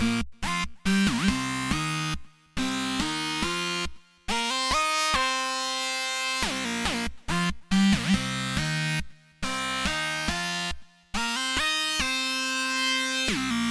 Ominous Flute Siren Stabs
标签： 140 bpm Trap Loops Flute Loops 2.31 MB wav Key : Unknown FL Studio
声道立体声